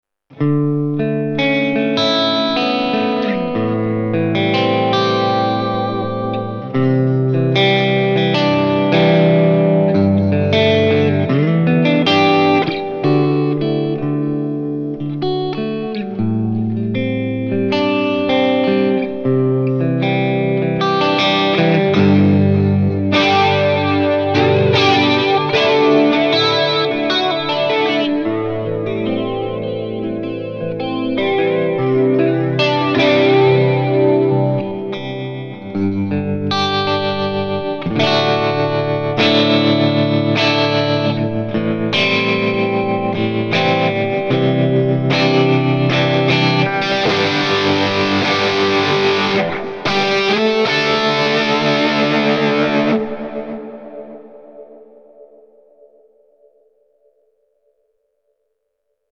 This Amp Clone rig pack is made from a Fuchs ODS 50 amp.
IR USED: MARSHALL 1960A V30 SM57+ E906 POS 1
RAW AUDIO CLIPS ONLY, NO POST-PROCESSING EFFECTS